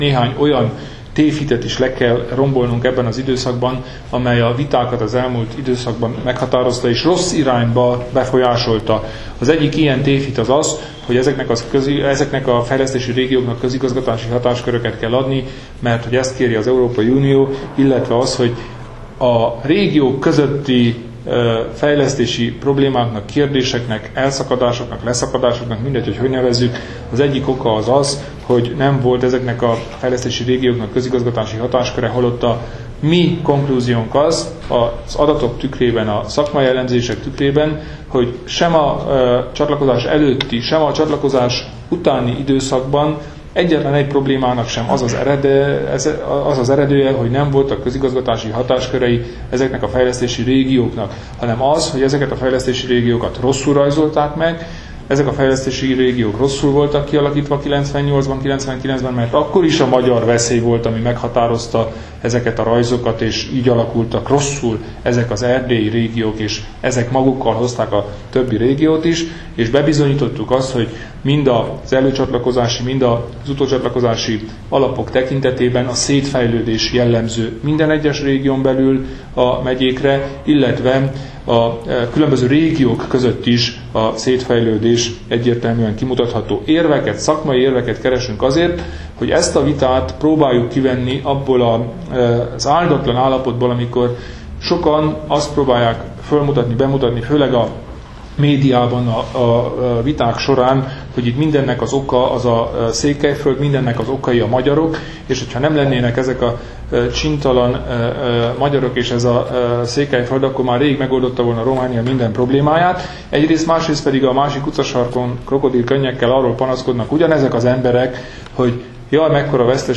„Egy olyan részletes szakmai anyaggal készült az RMDSZ a mai tanácskozásra, amilyent ebben a témában még nem mutatott be senki” – jelentette ki az RMDSZ elnöke a tanácskozást követő sajtótájékoztatón.
Kelemen Hunor nyilatkozata